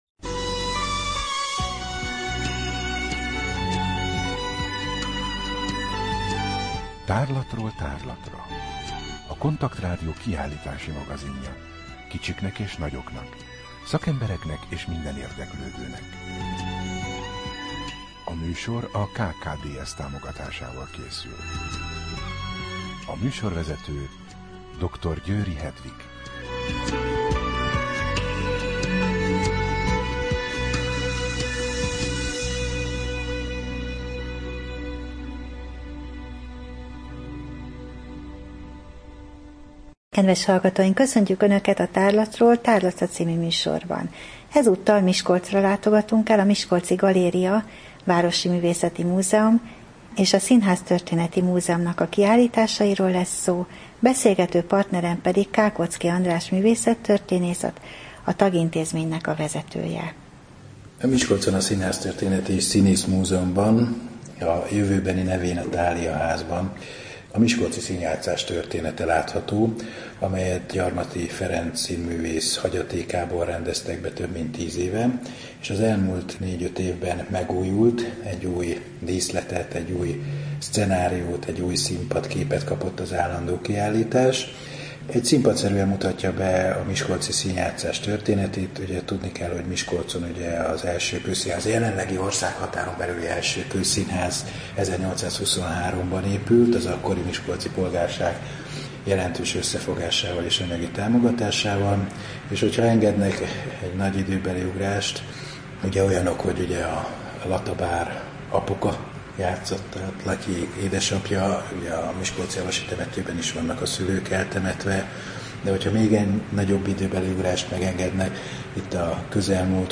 Rádió: Tárlatról tárlatra Adás dátuma: 2014, November 7 Tárlatról tárlatra / KONTAKT Rádió (87,6 MHz) 2014. november 7.